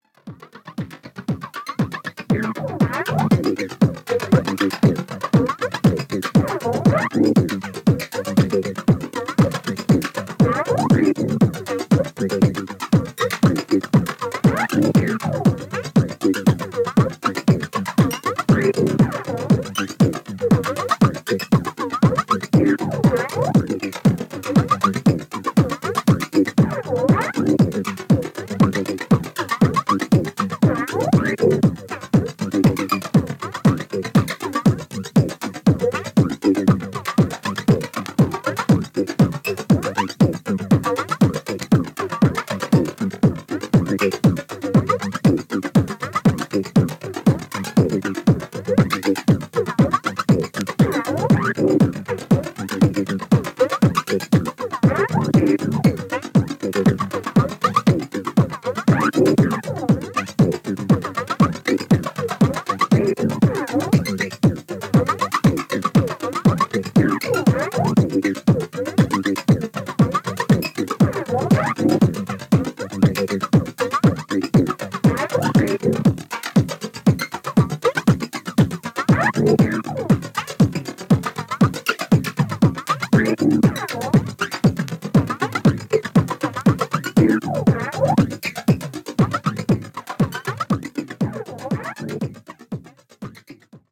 進化したACID,DEEP HOUSE感たまんないですね！！！